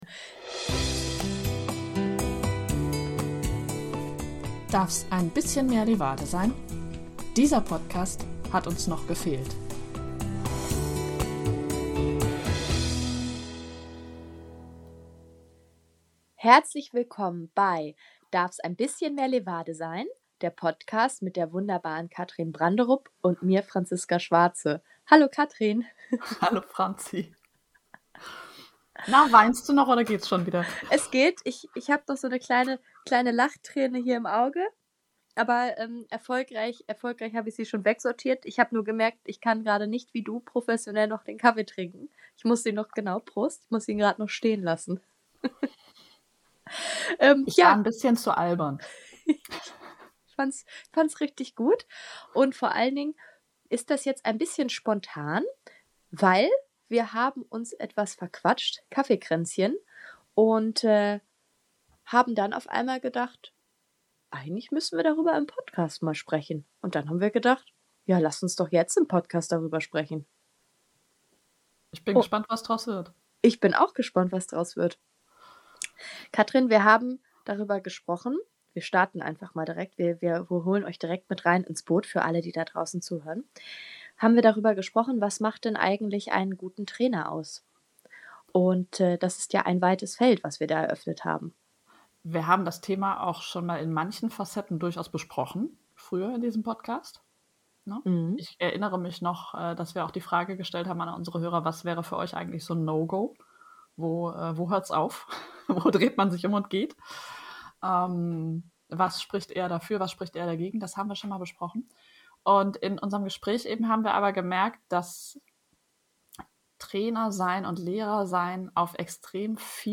In dieser spontanen Bonusfolge sprechen unsere beiden Podcasterinnen über ihre Erfahrungen in den Rollen als Schüler und Lehrer.